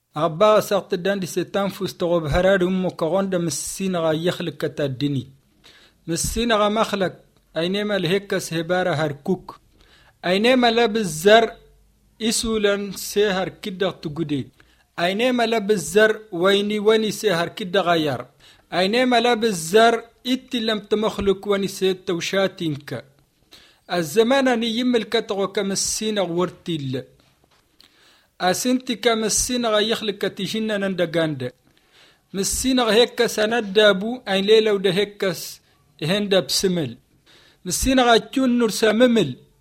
2 June 2014 at 5:18 pm The speaker has a French intonation somehow.